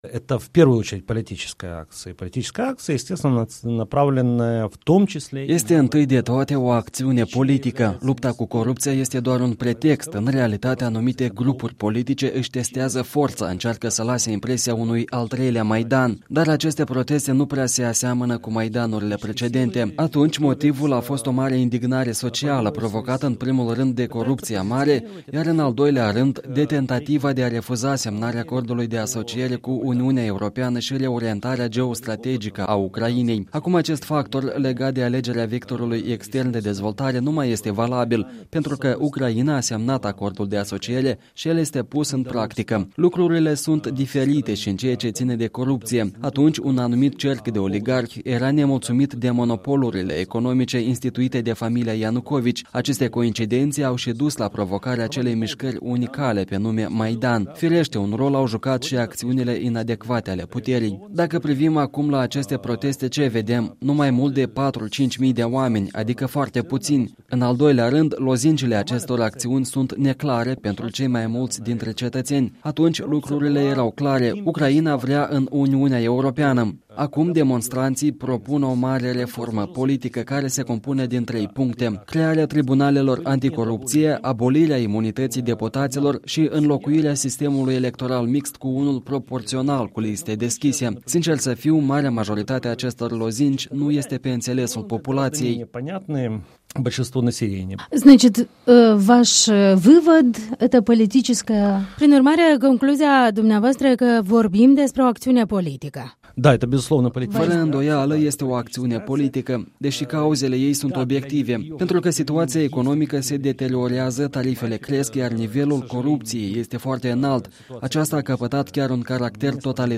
Interviu cu un analist politic ucrainean despre demonstrațiile de la Kiev.